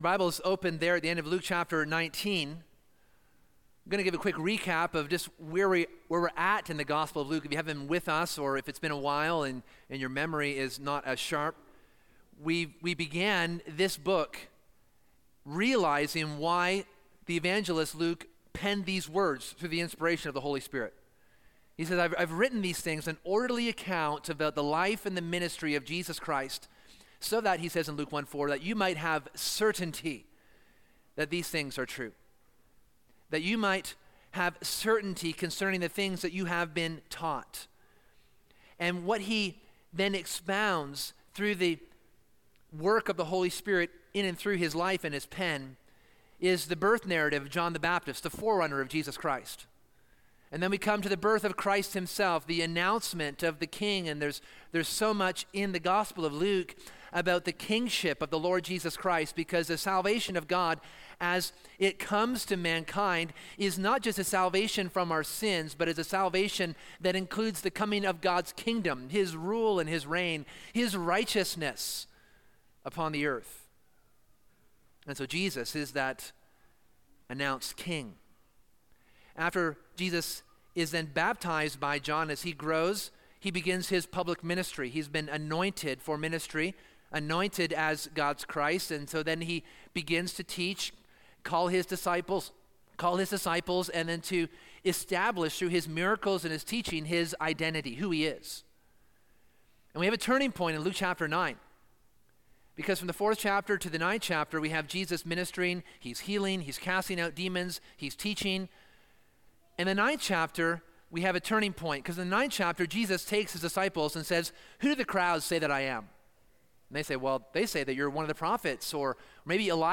In this sermon we consider the first attempt by the religious leaders to discredit Jesus as he teaches in the temple.